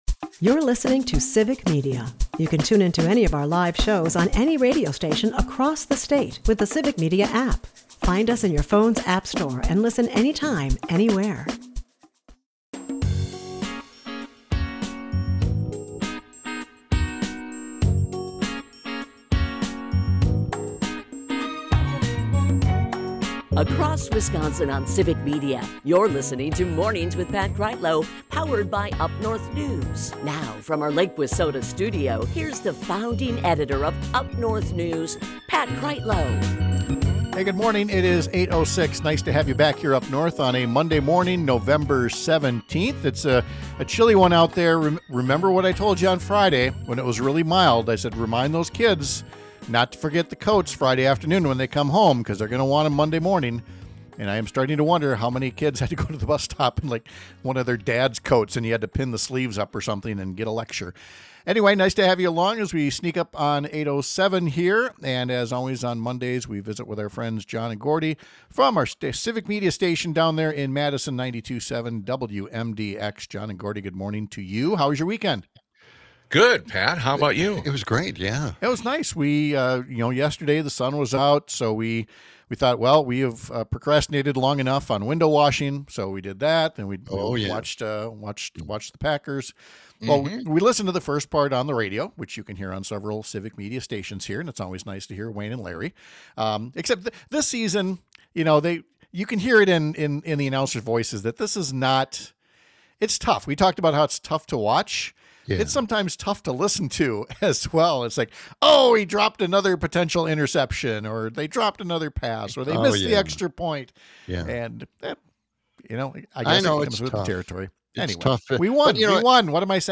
The latest example is the Knowles-Nelson Stewardship Program that protects some of our state’s most scenic and sensitive areas from being turned into strip malls, golf courses, and places that no longer allow snowmobiles and other recreation. We’ll talk to a state senator who’s working on a bipartisan effort to keep the program from becoming just another political or unfunded altogether.